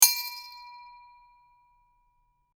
دانلود آهنگ اعلان خطر 8 از افکت صوتی اشیاء
جلوه های صوتی